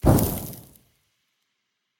Sound / Minecraft / mob / zombie / infect.ogg
should be correct audio levels.
infect.ogg